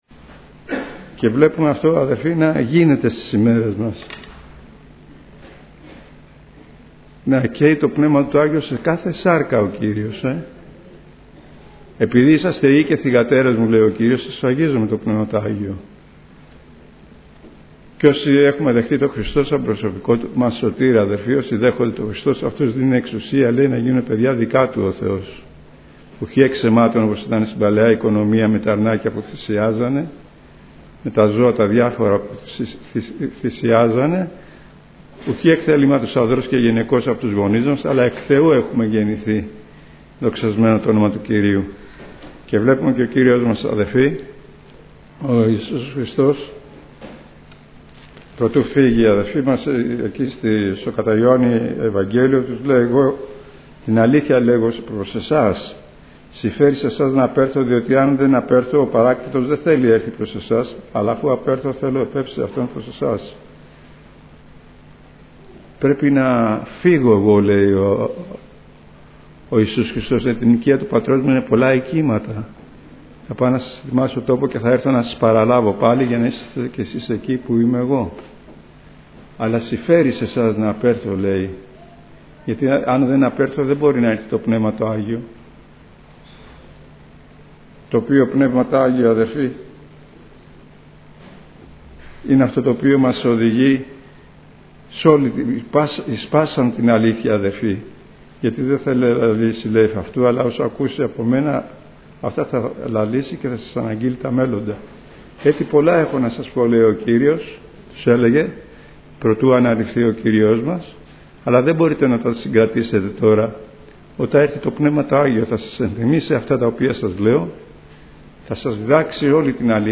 Ομολογίες Πνεύματος Αγίου Ομιλητής: Διάφοροι Ομιλητές Λεπτομέρειες Σειρά: Κηρύγματα Ημερομηνία: Δευτέρα, 28 Μαΐου 2018 Εμφανίσεις: 299 Γραφή: Πράξεις των Αποστόλων 2 Λήψη ήχου Λήψη βίντεο